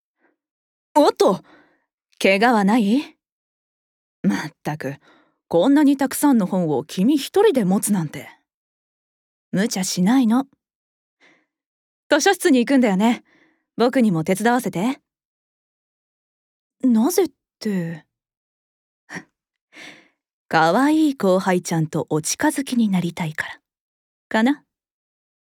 ボイスサンプル
少しキザな王子系女子